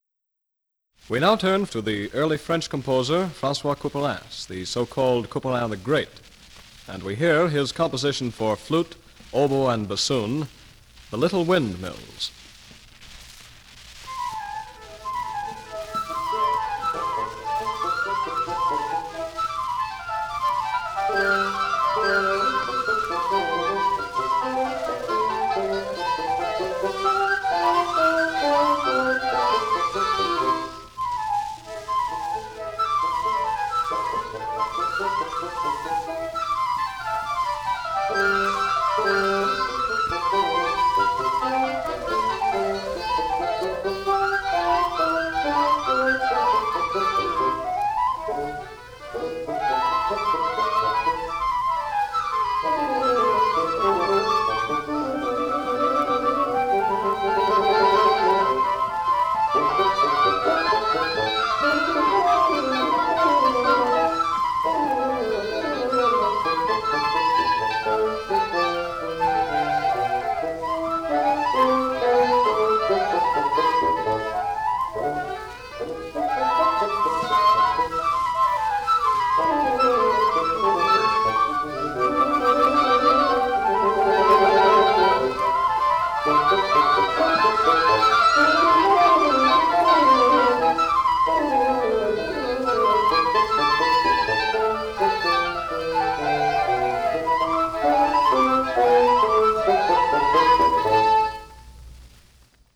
The following pieces were performed at the Curtis Institute of Music by various wind ensembles from 1936 to 1941 under the direction of Marcel Tabuteau.
Format: 78 RPM